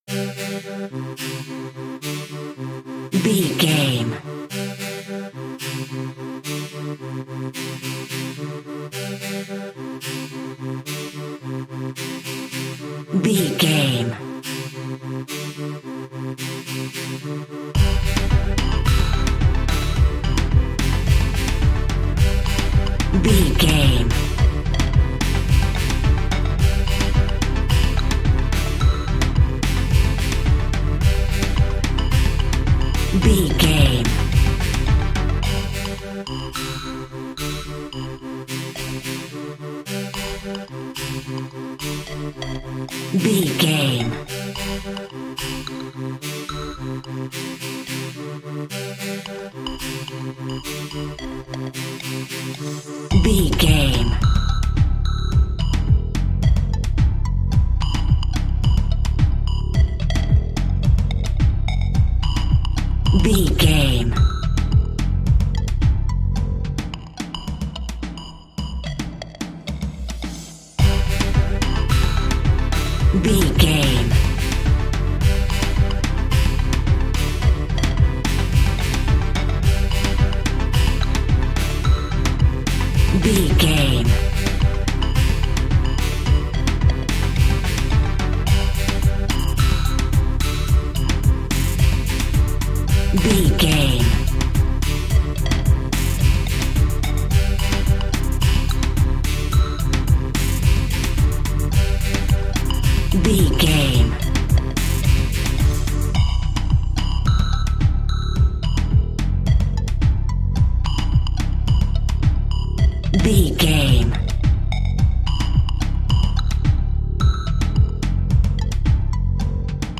Classic reggae music with that skank bounce reggae feeling.
Ionian/Major
dub
laid back
chilled
off beat
drums
skank guitar
hammond organ
percussion
horns